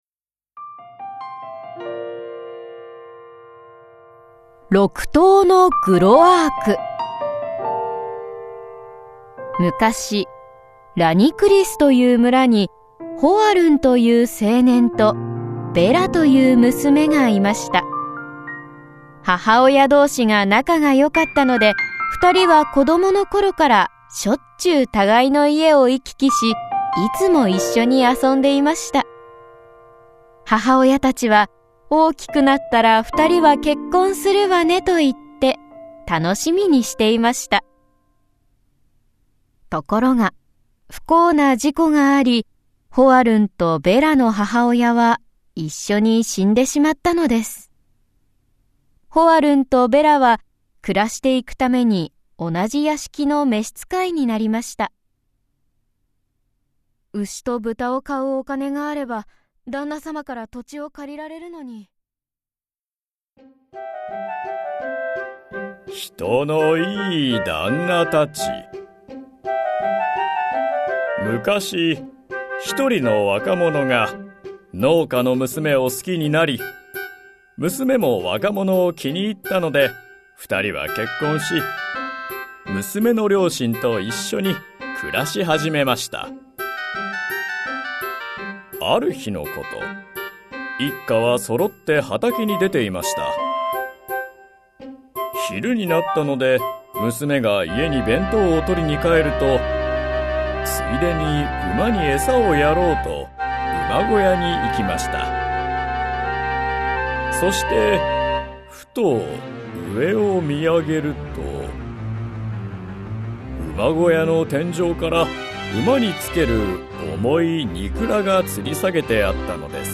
子どもの想像力を豊かに育む 昔話とファンタジーの読み聞かせCD
プロとして活躍する朗読家や声優、ナレーター達が感情豊かに読み上げます。